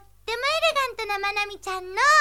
J'ai seulement reconnu la voix de Manami qui parle d'elle à la 3ème personne (ce qui est étonnant pour une jeune fille plutôt mature).